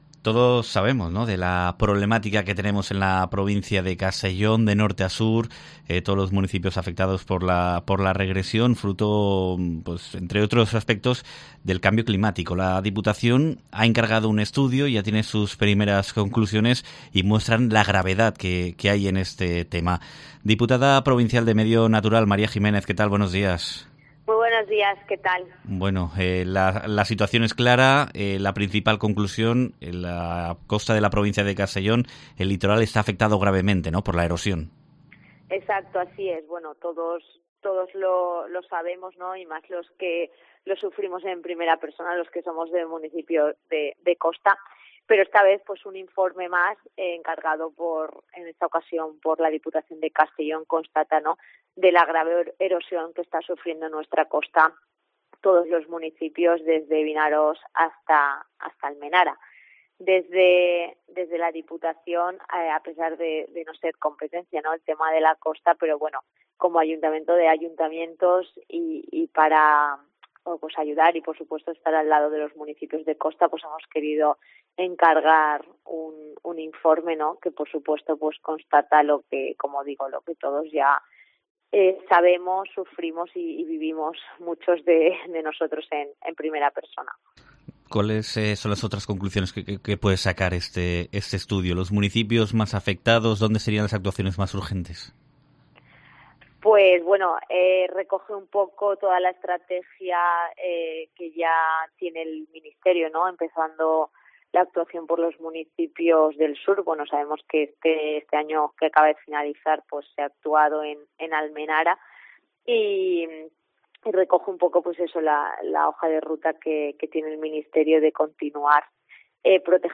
Entrevista
Un estudio encargado por la Diputación muestra que escolleras y rellenar las playas con arena, son algunas de las soluciones para frenar al regresión en la costa de Castellón, como explica en COPE la diputada provincial del Medio Natural, María Jiménez